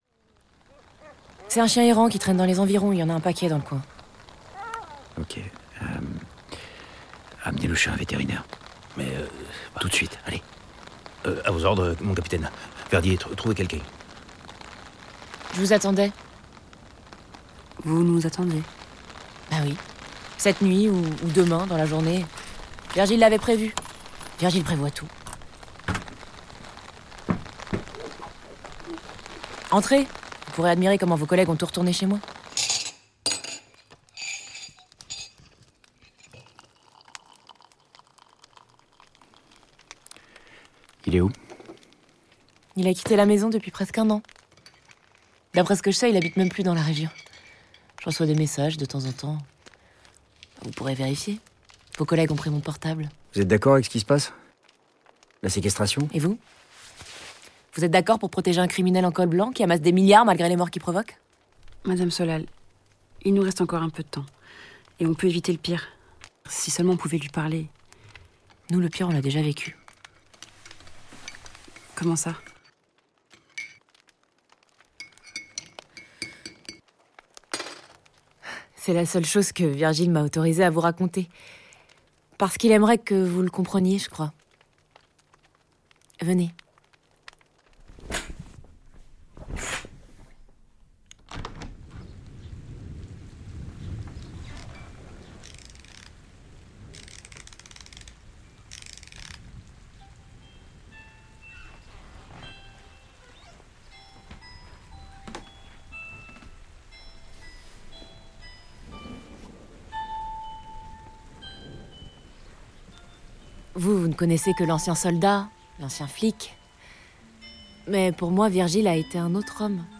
Impact, la série audio - Episode 2